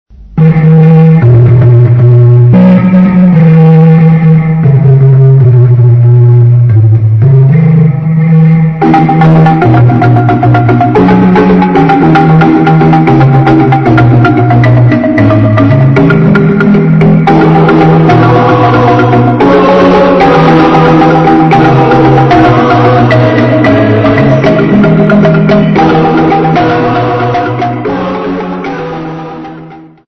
Folk music
Field recordings
Participants at Garankuwa workshop perform own composition with Marimba accompaniment.
7.5 inch reel
96000Hz 24Bit Stereo